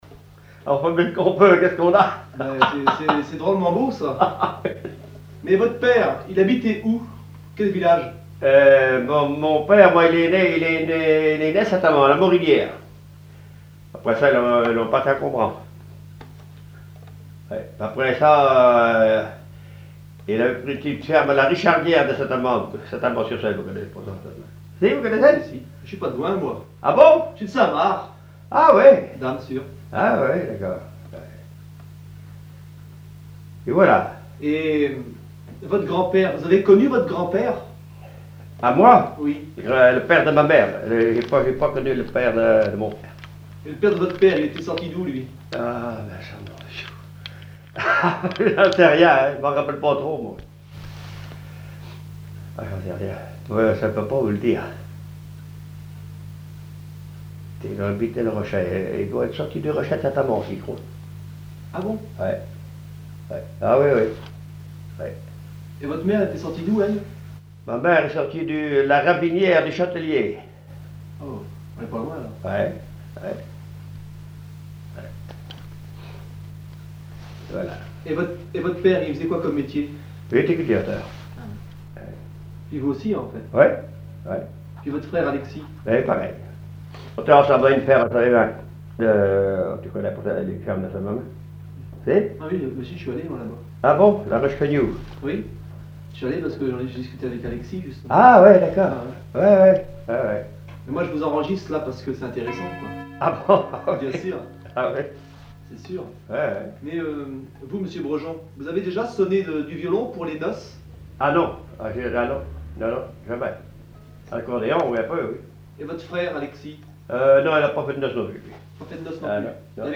Enquête Conservatoire des Musiques Anciennes et Traditionnelles de Vendée
répertoire d'air pour la danse au violon et à l'accordéon
Catégorie Témoignage